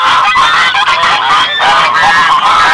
Geese And Ducks Sound Effect
Download a high-quality geese and ducks sound effect.
geese-and-ducks.mp3